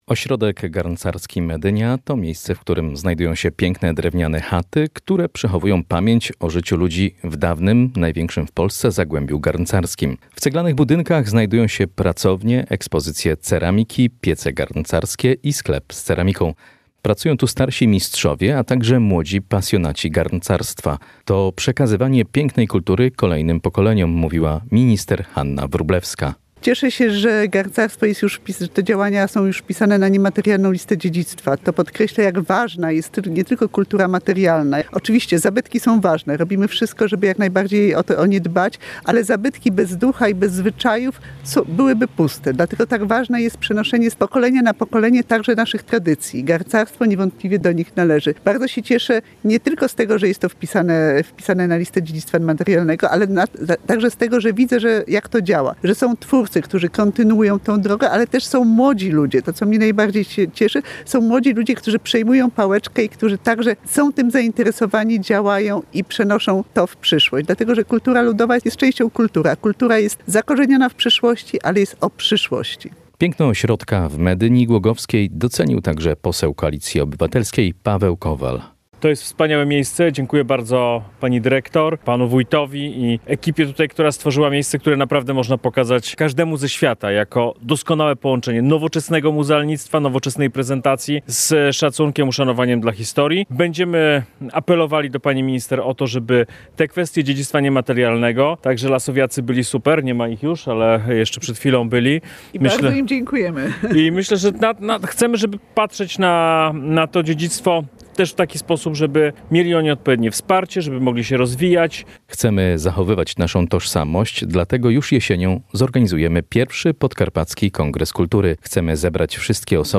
Wiadomości • Relacja